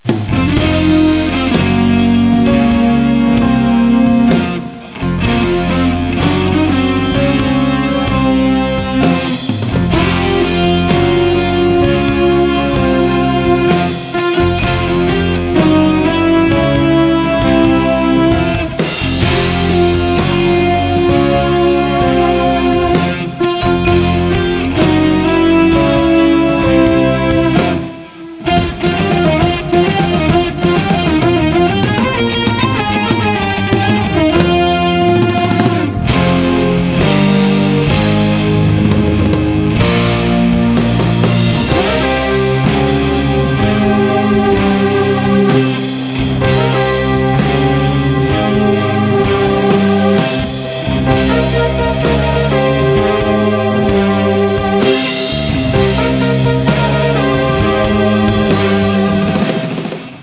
guitars
drums
vocals, bass and keyboards
recorded 8 track digital adat sep'95 through oct'96